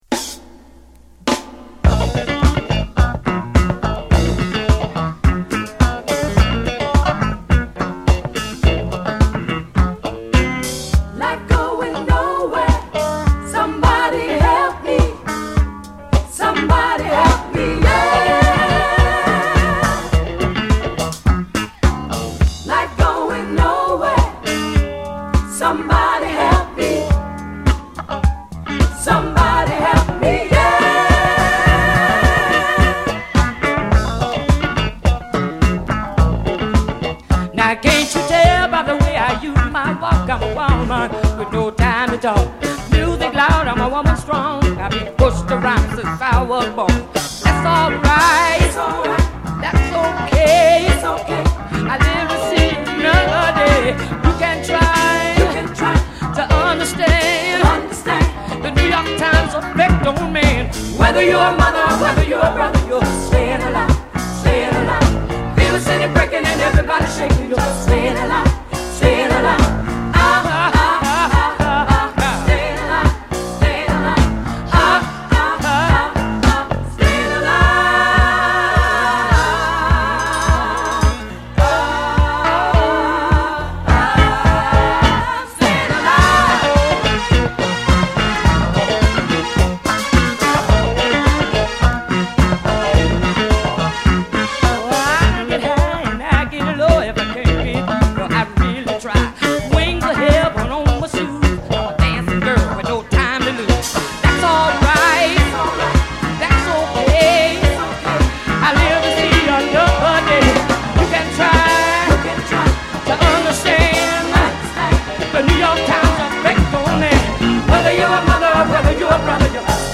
オリジナルよりも渋い黒さが出たナイスなミッド・テンポ・ディスコ！